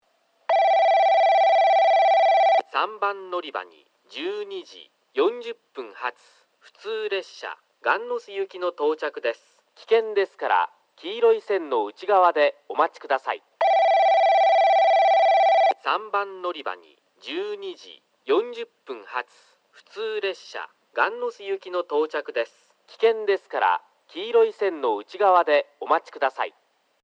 その為西戸崎、雁ノ巣行きの男声放送を聞くことができるようになり、さらには香椎行きの放送も聞けるのが大きな特徴です。
3番のりば接近放送（雁ノ巣行き）
※香椎線ホームの接近ベルは省略しております。